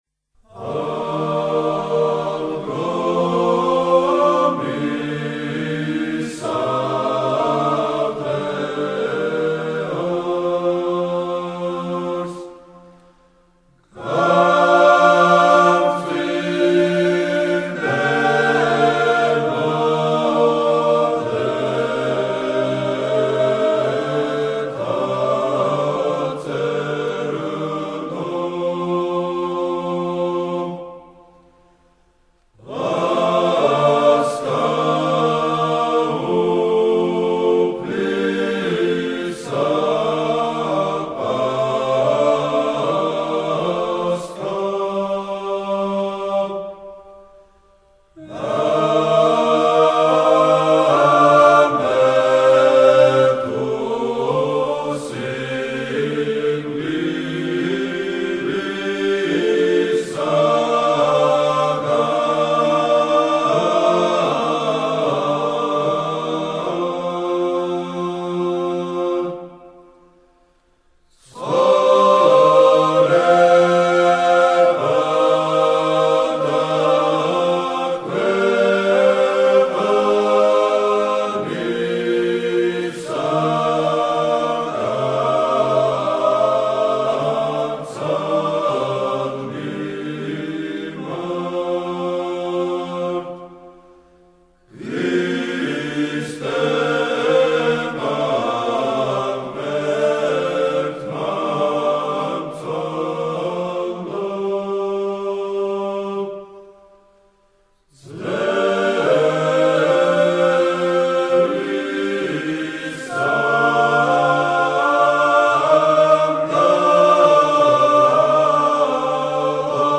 საგალობელი
გუნდი